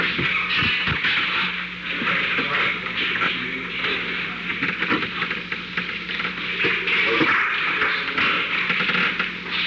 Secret White House Tapes
Conversation No. 638-7
Location: Oval Office
The President met with Alexander P. Butterfield.